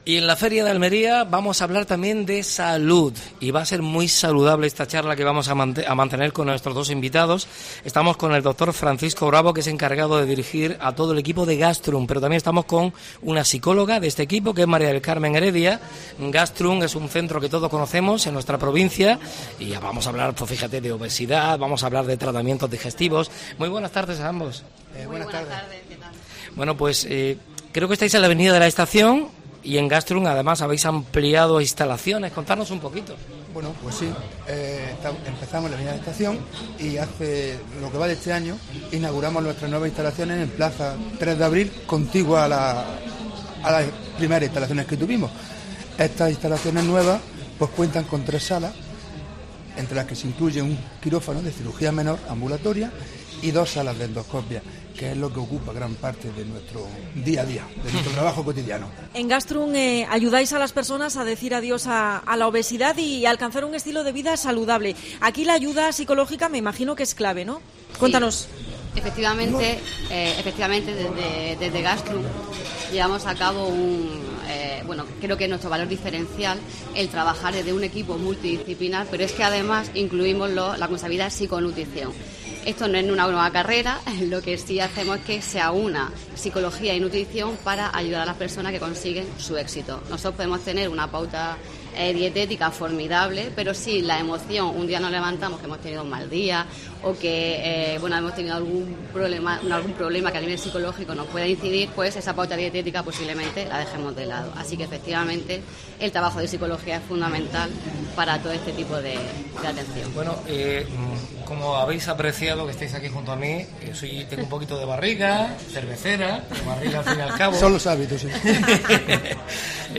AUDIO: Programa especial de la Feria de Almería desde el Hotel Torreluz.